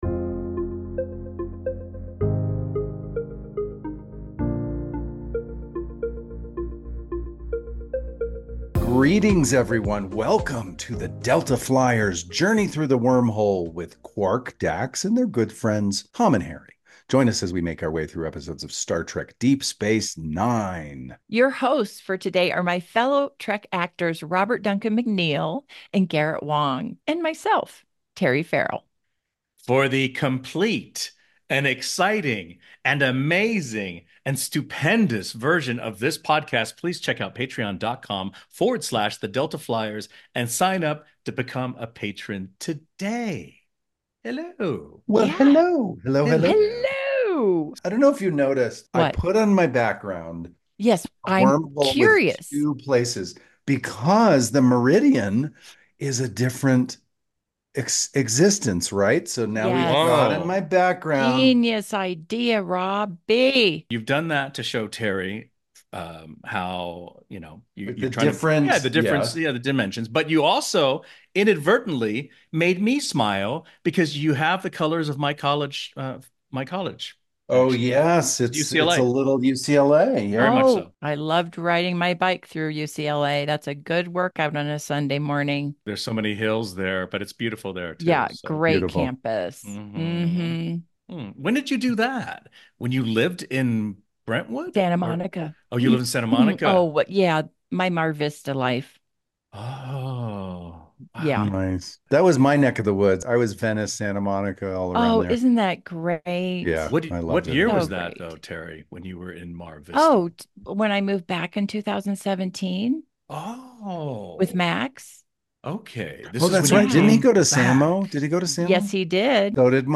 Join Garrett Wang, Robert Duncan McNeill, Terry Farrell, and Armin Shimerman as they re-watch and discuss Star Trek episodes. You will hear exclusive behind the scene stories from those who were there!